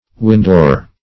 Search Result for " windore" : The Collaborative International Dictionary of English v.0.48: Windore \Win"dore\, n. [A corrupt. of window; or perh. coined on the wrong assumption that window is from wind + door.]